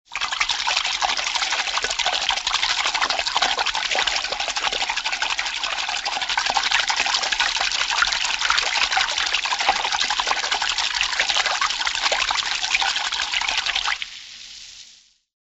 На этой странице собраны разнообразные звуки воды: журчание ручья, шум прибоя, капли дождя и плеск водопада.
рыба играет в воде